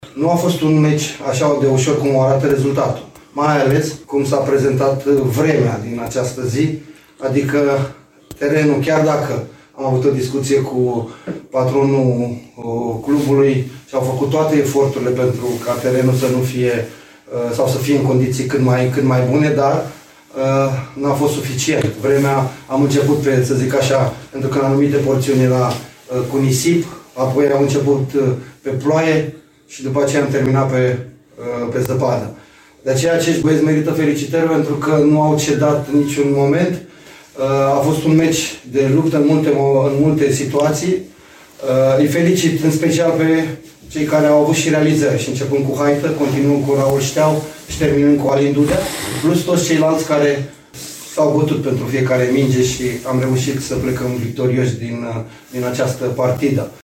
La final de meci, antrenorul Flavius Stoican nu a putut omite condițiile meteo, în care s-a jucat, dar a fost generos și cu laudele la adresa jucătorilor lui: